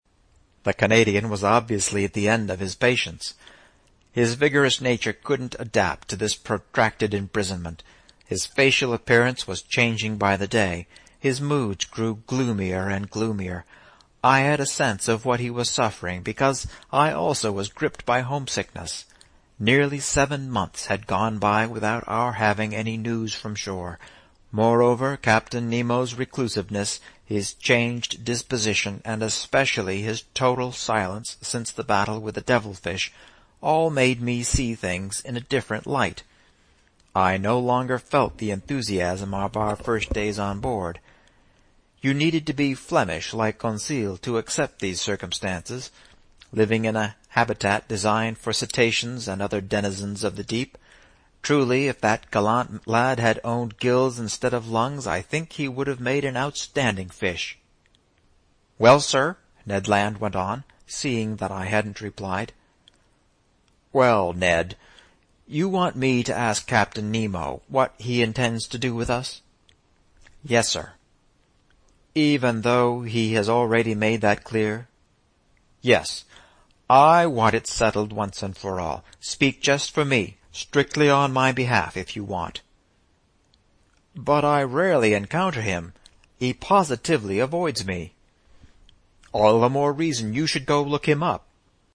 英语听书《海底两万里》第517期 第32章 海湾暖流(8) 听力文件下载—在线英语听力室
在线英语听力室英语听书《海底两万里》第517期 第32章 海湾暖流(8)的听力文件下载,《海底两万里》中英双语有声读物附MP3下载